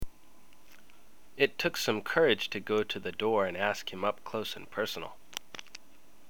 今日の発音
[発音]